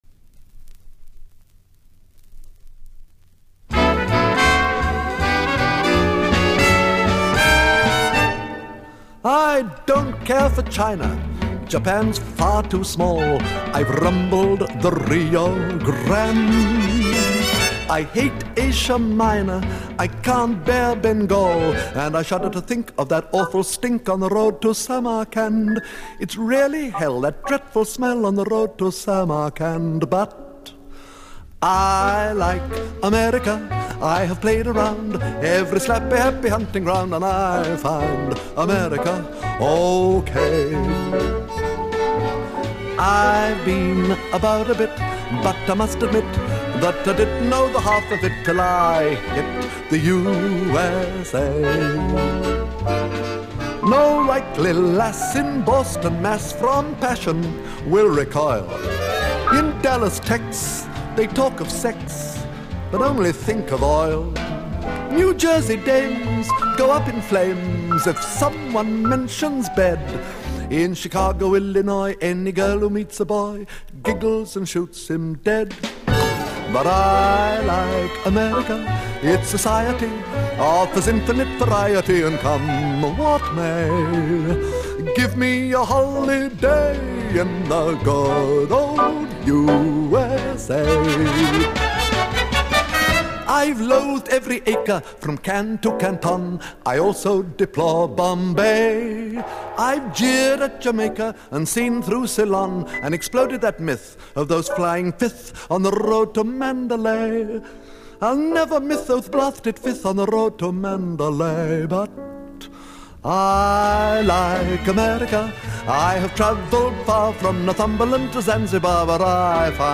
Here’s a tune I captured from an old album I found.